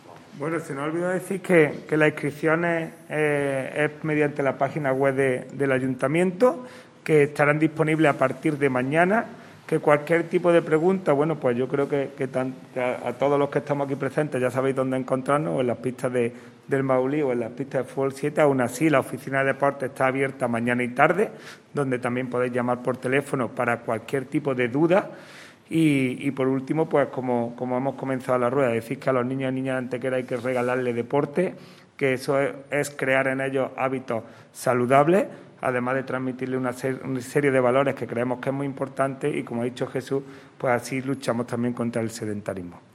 El teniente de alcalde delegado de Deportes, Juan Rosas, ha presentado hoy en rueda de prensa una nueva iniciativa del Área de Deportes a desarrollar con motivo de los días escolares no lectivos que conlleva el tradicional desarrollo en nuestra provincia de las denominadas como vacaciones de Semana Blanca.
Cortes de voz